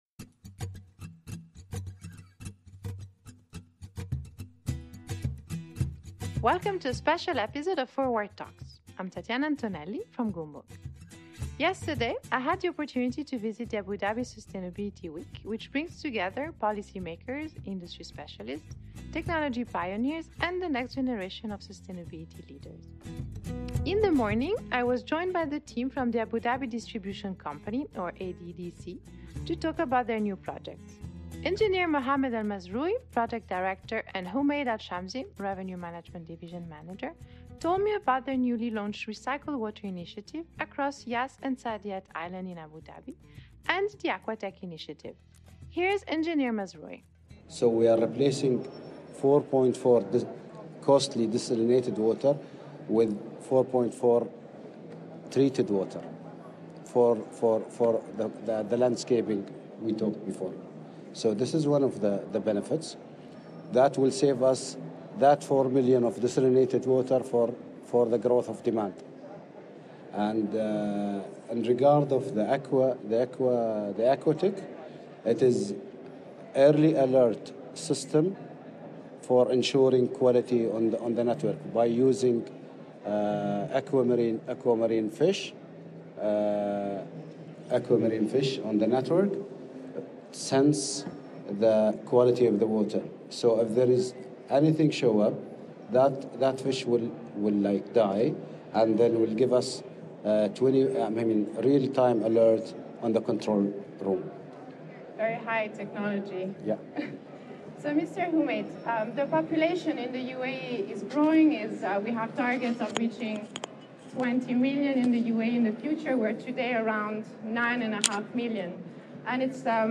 From Abu Dhabi Sustainability Week: Recycled water & AquaTEC